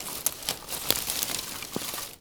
wood_tree_branch_move_17.wav